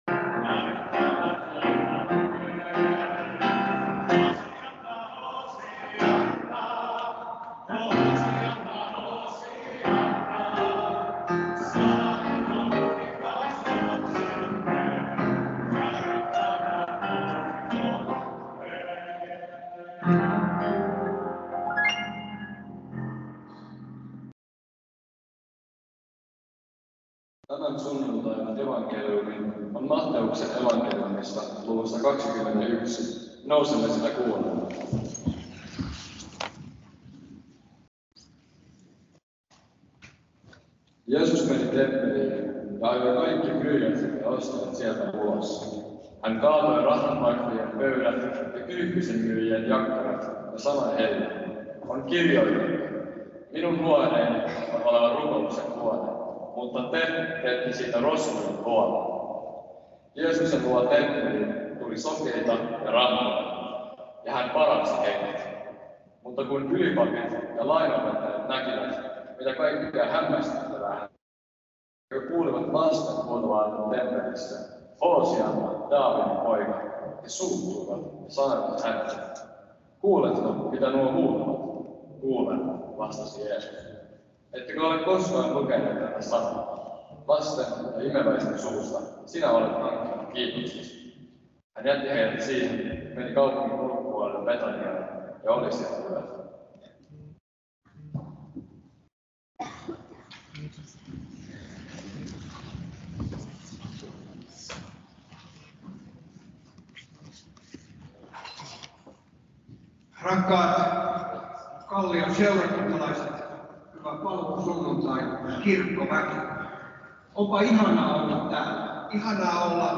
主日证道： 主题： 耶稣基督已经复活 证道: 本会牧师 经文： 1.